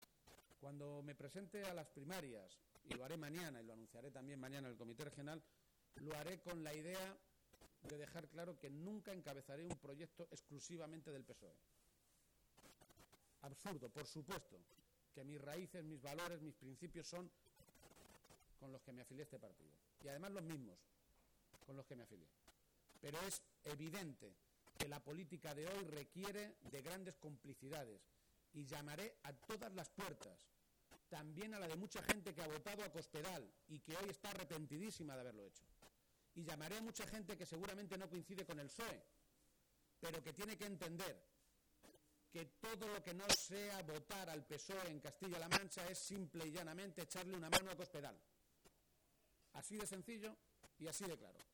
El secretario general del PSOE de Castilla-La Mancha, Emiliano García-Page, ha visitado hoy la Feria de Talavera y allí, a 24 horas de hacer oficial su candidatura a las primarias para ser quien compita por la Presidencia de Castilla-La Mancha, ha hecho un contundente anuncio:”Si en Mayo soy el Presidente de Castilla-La Mancha y antes de Agosto no hay encima de la mesa un Plan de Empleo para 60.000 parados, yo dimitiré”.